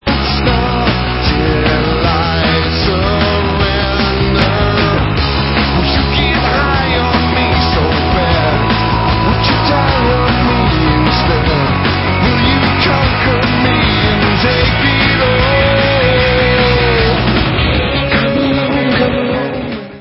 Allstar dutch rockband w/ex-anouk & kane members